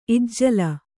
♪ ijjala